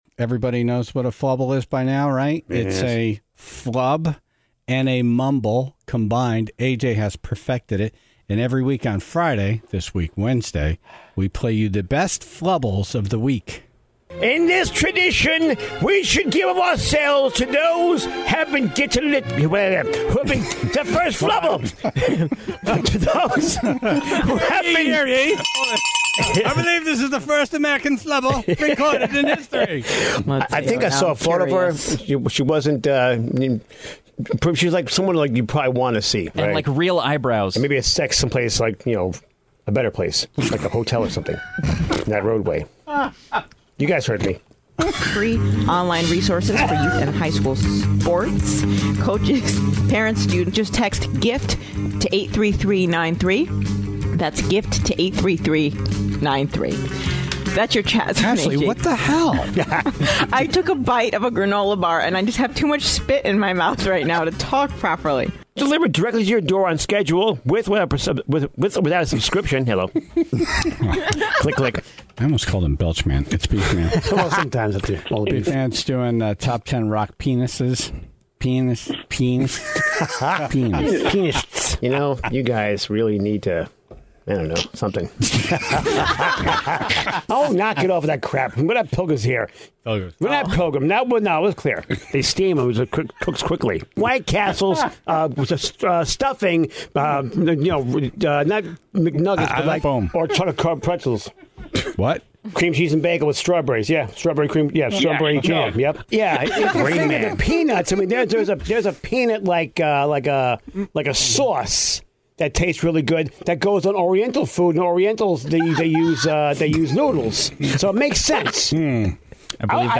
Even during a short week because of Thanksgiving, the flubble montage had plenty to offer.